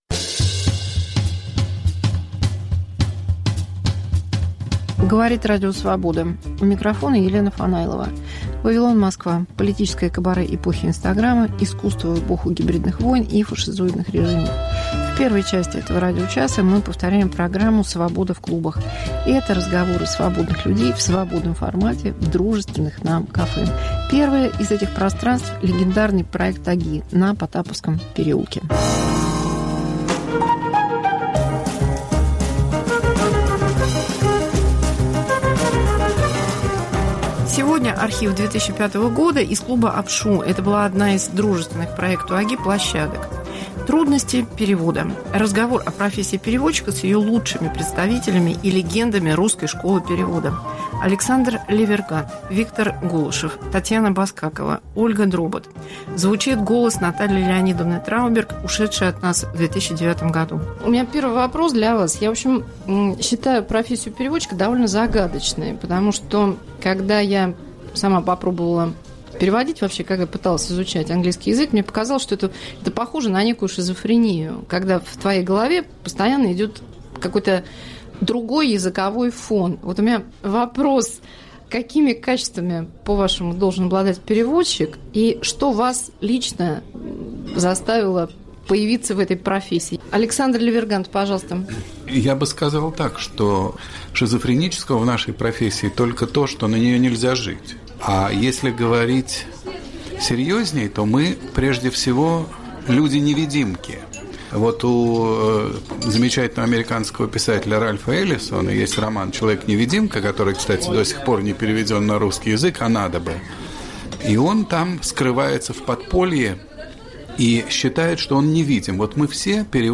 Мегаполис Москва как Радио Вавилон: современный звук, неожиданные сюжеты, разные голоса. 1. Архив "Свободы в клубах" с легендами русской школы перевода. 2. Новые переводы сонетов Шекспира